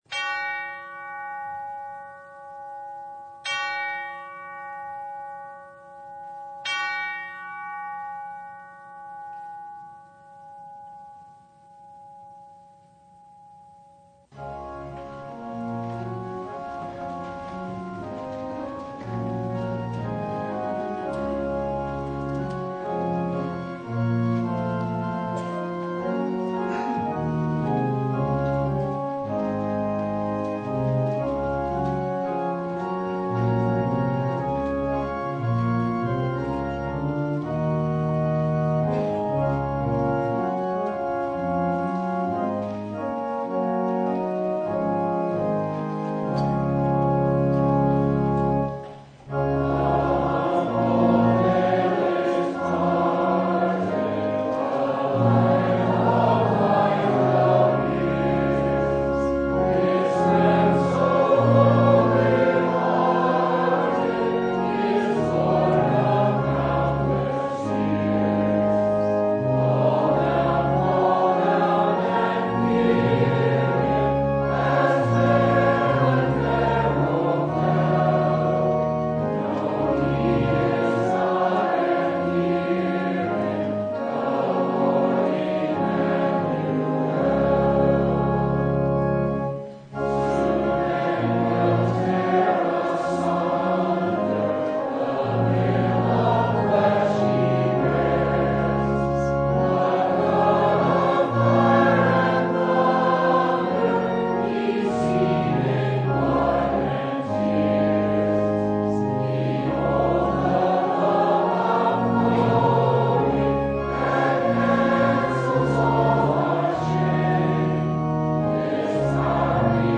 Full Service